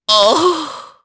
Oddy-Vox_Dead_jp.wav